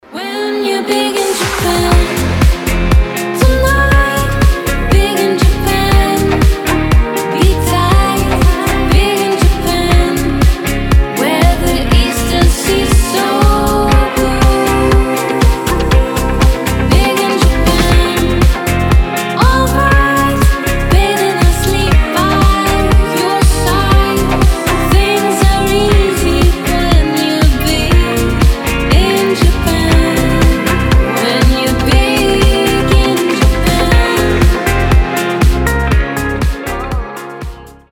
• Качество: 320, Stereo
красивые
deep house
retromix
нежные